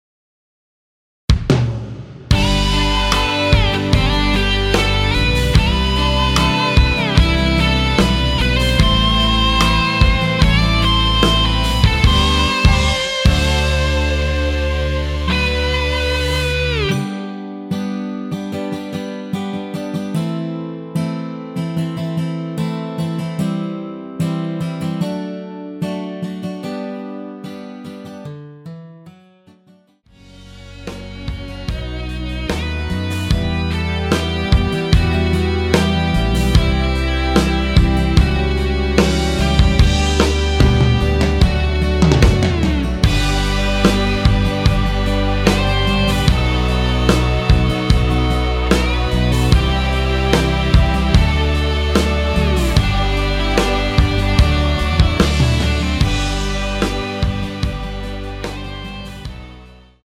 최고의 음질, 다른 곳 MR은 노래방 느낌이 나는데 원곡과 99% 흡사하네요.
앞부분30초, 뒷부분30초씩 편집해서 올려 드리고 있습니다.
중간에 음이 끈어지고 다시 나오는 이유는